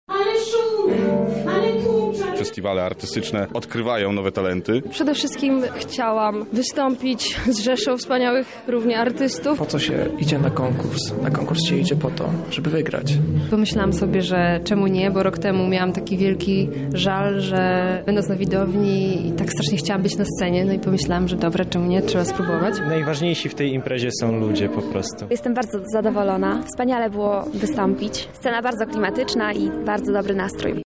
Zapytaliśmy uczestników, jak oceniają festiwal.